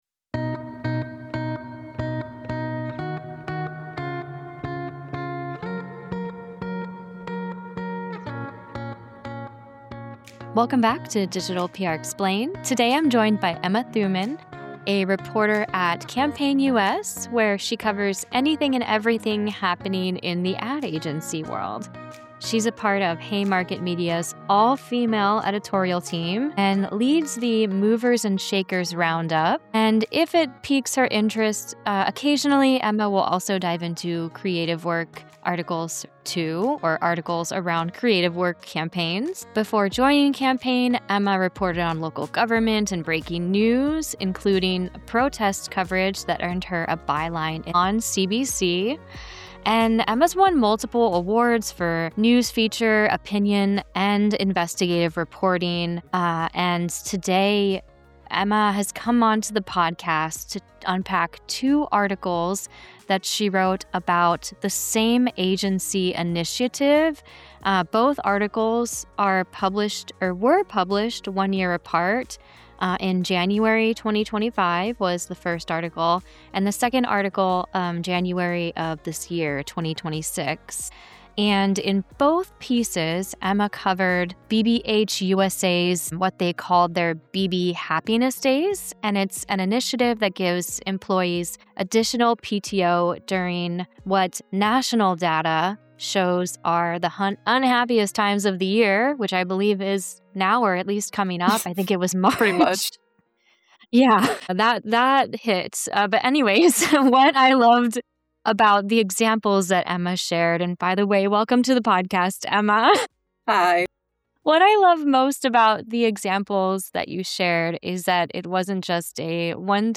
This conversation breaks down what actually gets a reporter’s attention, why some stories evolve into follow-ups, and how PRs can think beyond the first piece of coverage.